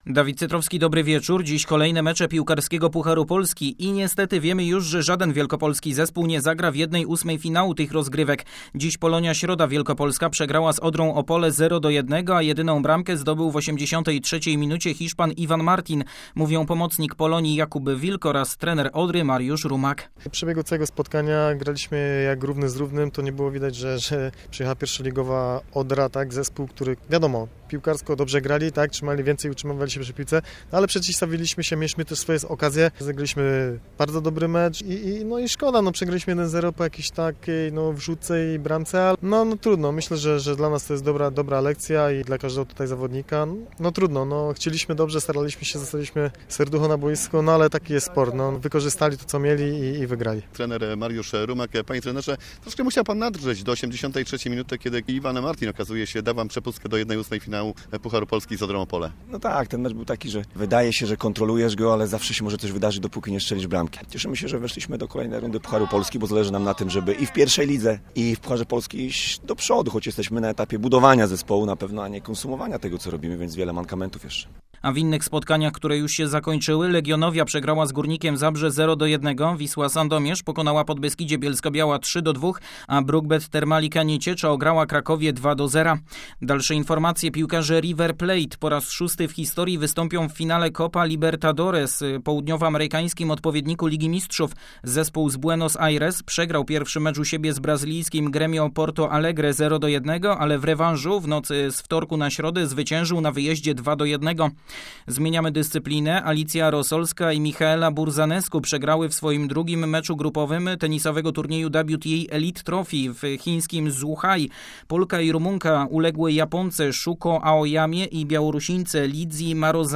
31.10. serwis sportowy godz. 19:05